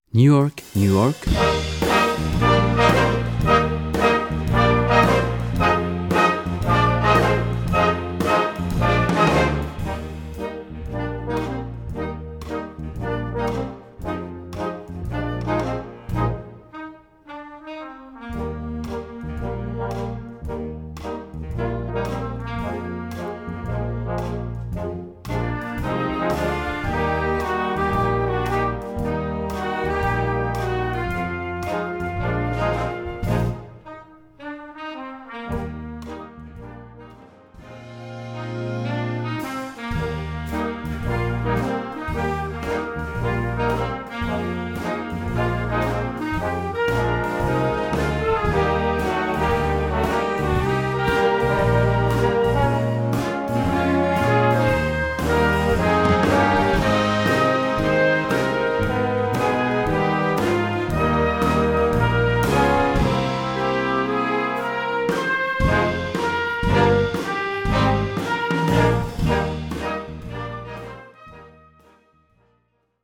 Gattung: Jugendwerk
Besetzung: Blasorchester